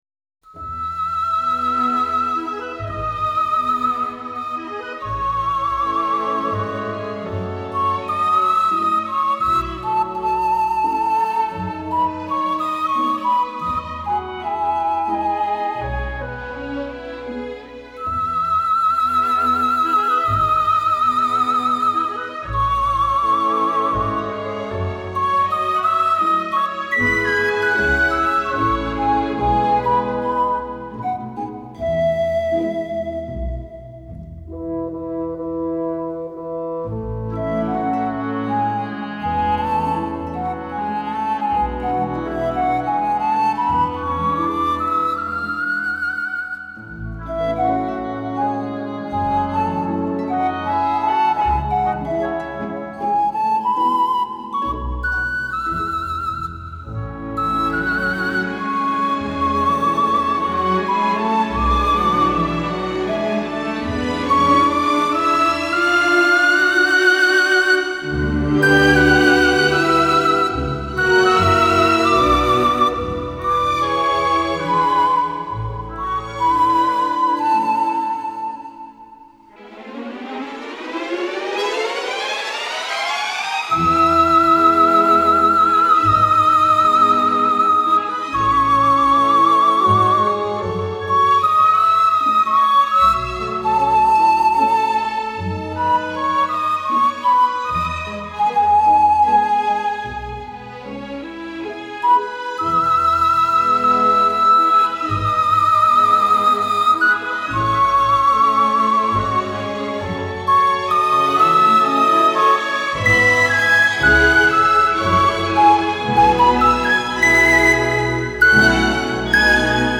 排笛音域宽广，音区变化大，音色丰富。